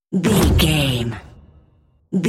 Dramatic hit slam door
Sound Effects
heavy
intense
dark
aggressive